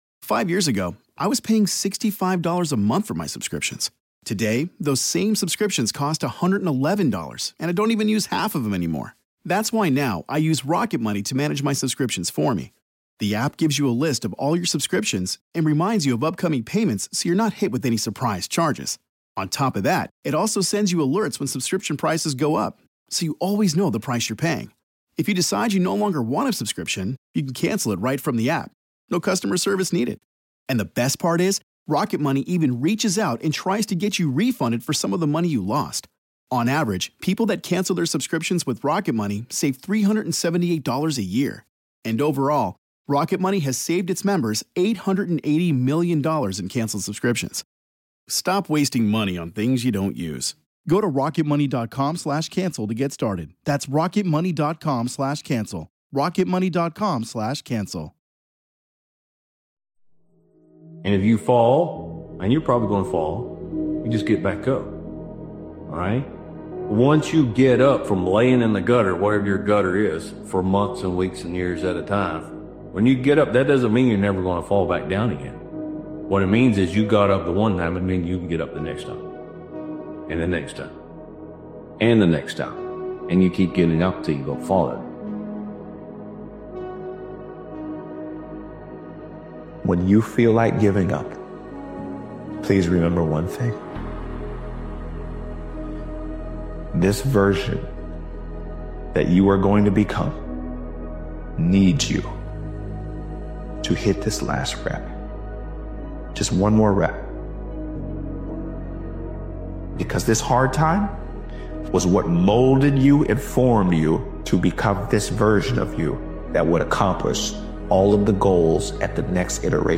This powerful motivational speeches compilation is about returning to the mindset that once made you dangerous—the focus, hunger, and discipline you had when excuses didn’t exist. When distractions creep in and standards slip, this is your reminder to lock back in, raise the bar, and move with purpose again.